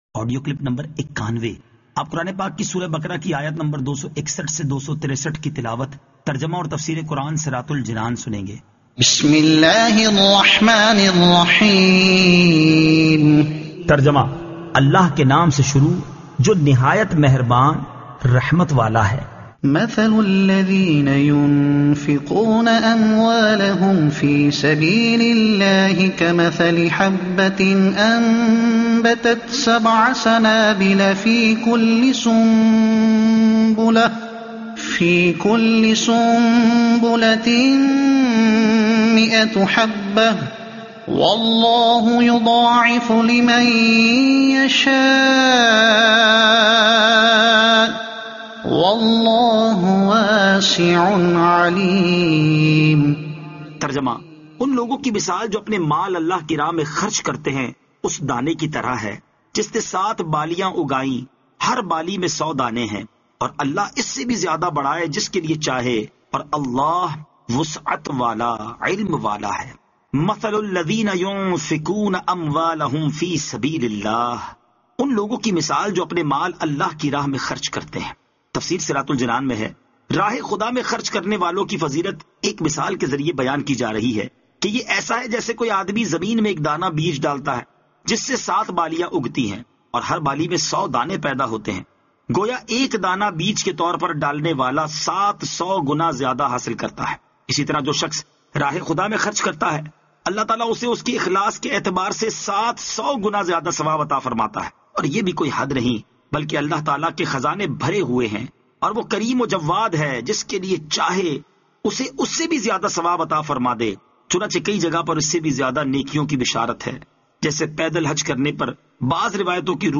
Surah Al-Baqara Ayat 261 To 263 Tilawat , Tarjuma , Tafseer